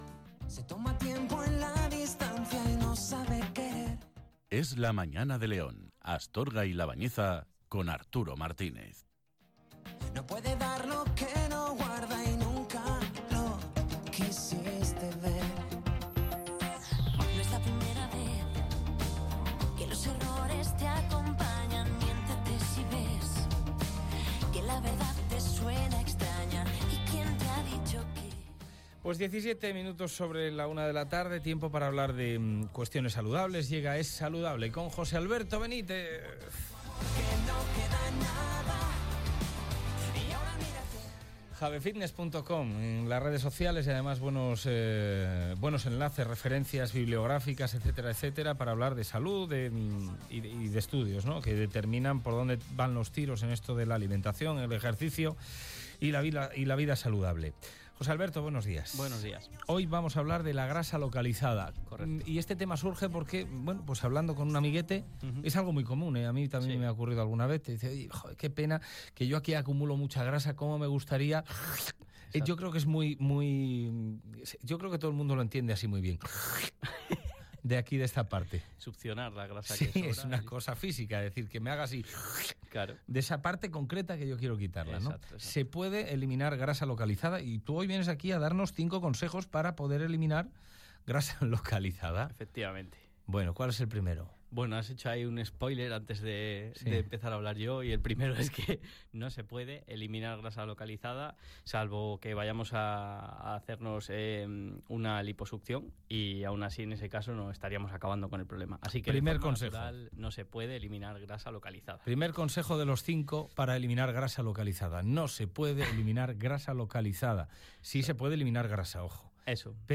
Hoy os traigo el centésimo vigésimo sexto programa de la sección que comenzamos en la radio local hace un tiempo y que hemos denominado Es Saludable.